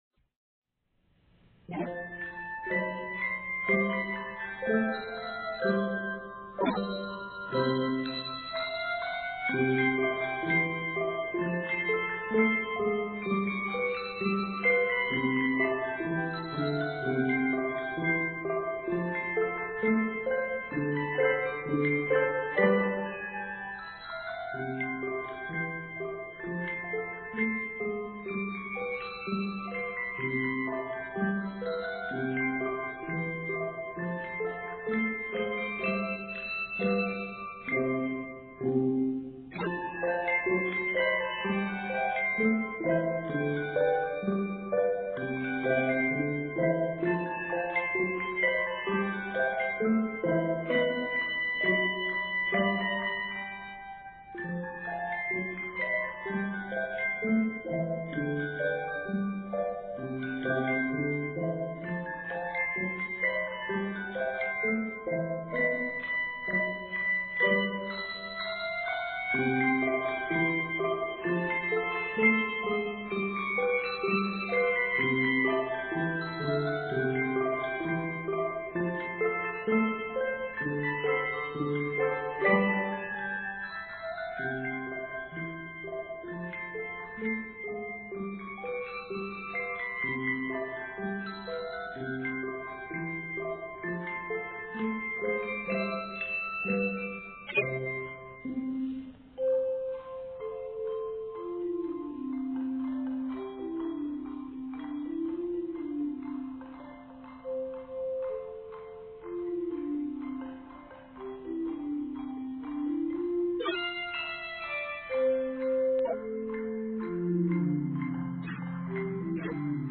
fun and original rag